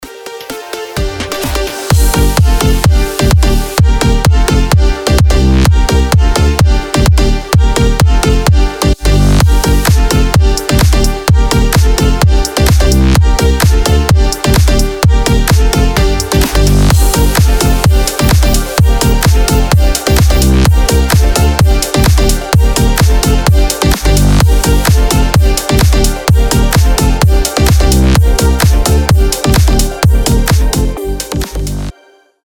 • Качество: 320, Stereo
громкие
EDM
без слов
пианино
динамичные
Стиль: slap house, Brazilian bass